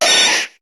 Cri de Sabelette dans Pokémon HOME.